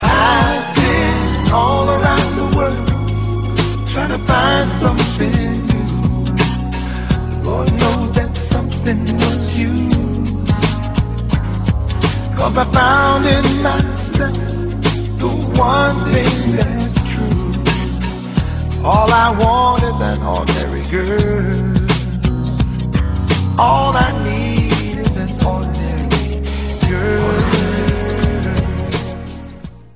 background vocals, drum programming and keyboards